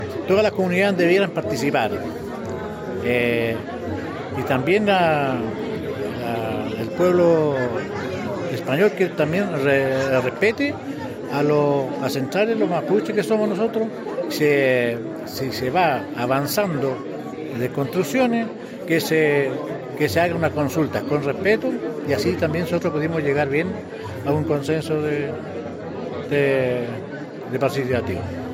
La actividad, realizada en la sala de Concejo de la Municipalidad de Temuco, reunió a autoridades comunales, regionales, ancestrales, dirigentes y dirigentas sociales, además de representantes del mundo público y privado.